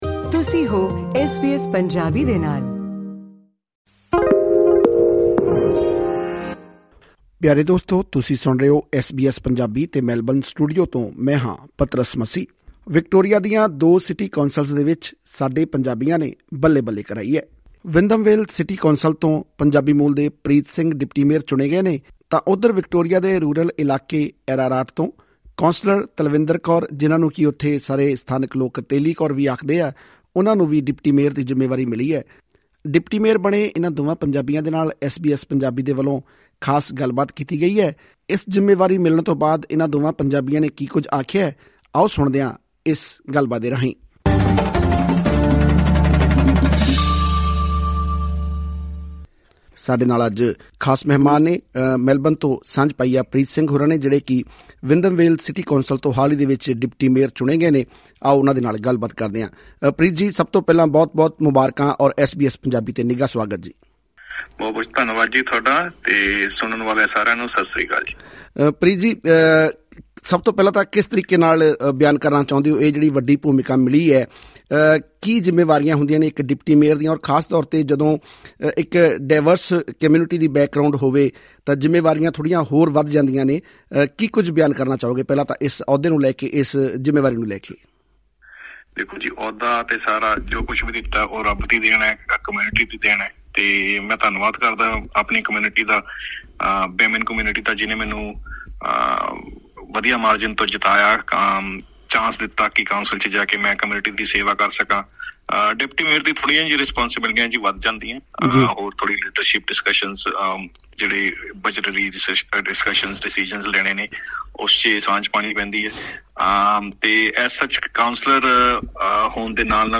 ਵਿਕਟੋਰੀਆ ਦੇ ਮੈਲਬਰਨ 'ਚ ਪੈਂਦੇ ਵਿੰਧਮ ਵੇਲ ਸਿਟੀ ਕੌਂਸਿਲ ਤੋਂ ਪ੍ਰੀਤ ਸਿੰਘ ਅਤੇ ਰੀਜਨਲ ਇਲਾਕੇ ਐਰਾਰਾਟ ਤੋਂ ਤਲਵਿੰਦਰ ਕੌਰ ਨੇ ਡਿਪਟੀ ਮੇਅਰ ਬਣ ਕੇ ਭਾਈਚਾਰੇ ਦਾ ਮਾਣ ਵਧਾਇਆ ਹੈ। ਐਸ ਬੀ ਐਸ ਪੰਜਾਬੀ ਨਾਲ ਗੱਲਬਾਤ ਕਰਦਿਆਂ ਇਨ੍ਹਾਂ ਦੋਵਾਂ ਪੰਜਾਬੀਆਂ ਨੇ ਇਸ ਅਹਿਮ ਜਿੰਮੇਵਾਰੀ ਲਈ ਜਿੱਥੇ ਸਮੂਹ ਭਾਈਚਾਰੇ ਅਤੇ ਆਪੋ-ਆਪਣੇ ਇਲਾਕਿਆਂ ਦੇ ਵੋਟਰਾਂ ਦਾ ਧੰਨਵਾਦ ਕੀਤਾ ਹੈ, ਉੱਥੇ ਹੀ ਲੋਕਾਂ ਦੀਆਂ ਆਸਾਂ-ਉਮੀਦਾਂ ’ਤੇ ਖਰੇ ਉਤਰਨ ਦੀ ਗੱਲ ਵੀ ਆਖੀ ਹੈ।